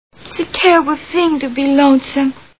Gentlemen Prefer Blondes Movie Sound Bites